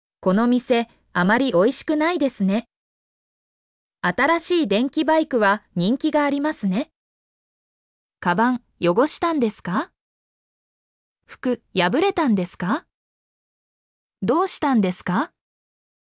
mp3-output-ttsfreedotcom-29_7O5oANuV.mp3